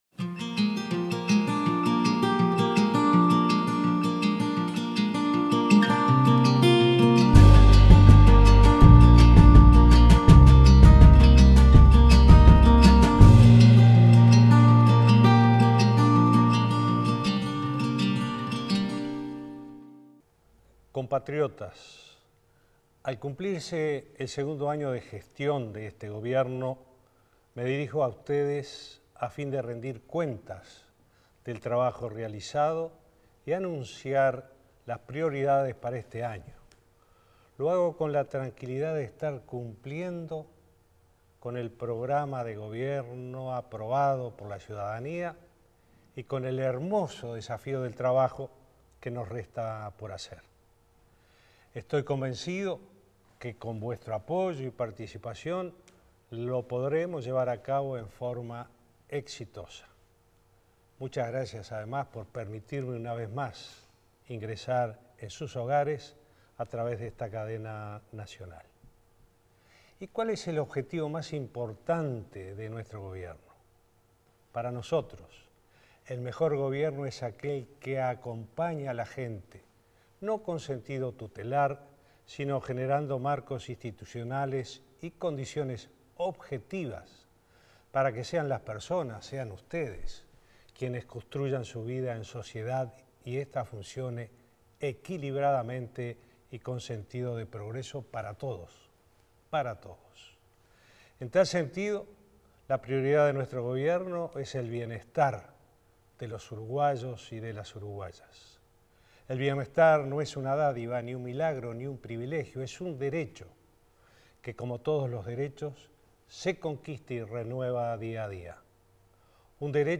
Vázquez realizó balance de gestión y destacó logros de políticas sociales y económicas 02/03/2017 Compartir Facebook X Copiar enlace WhatsApp LinkedIn Al cumplirse el segundo año de su mandato, el presidente Tabaré Vázquez se dirigió este miércoles a la población para realizar un balance de lo actuado y delinear las acciones a futuro para el resto del quinquenio. En transmisión simultánea de radio y televisión, Vázquez habló de las políticas sociales y económicas que han permitido el crecimiento y desarrollo del país en los últimos años.